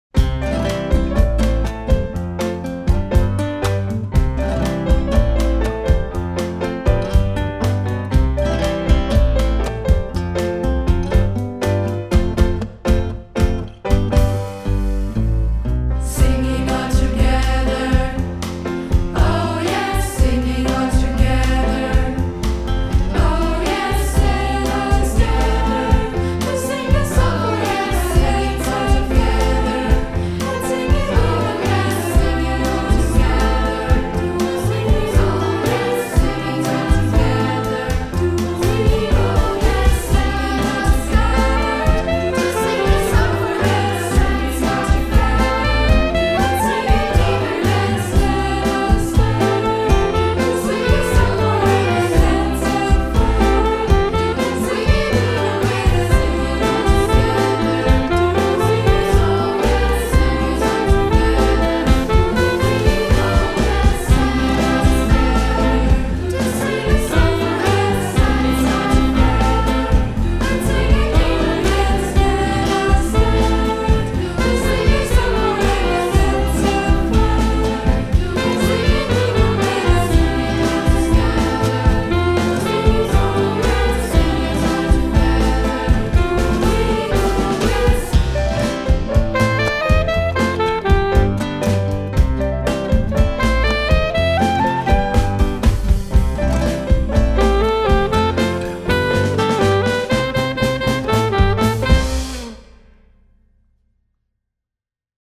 Einspielung als Kanon